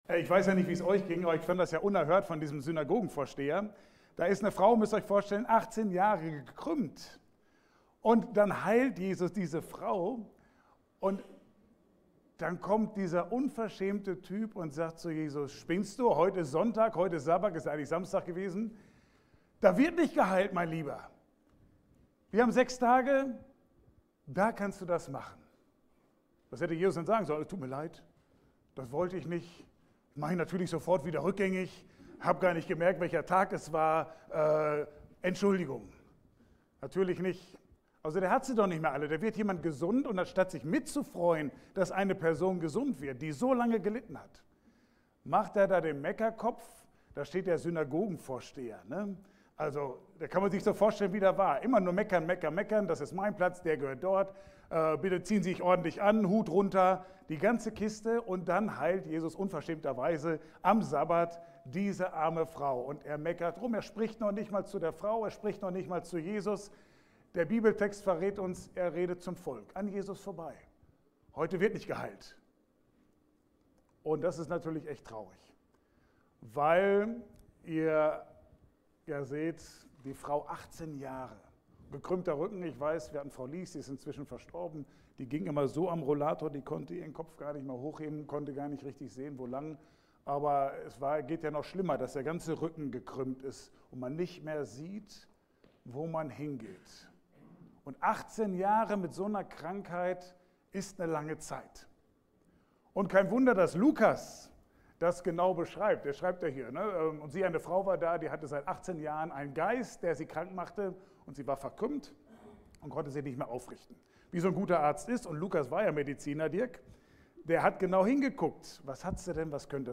Passage: Lukas 13, 10-17 Dienstart: Gottesdienst « Nur durch den Glauben an Jesus Christus Glaubensspagat?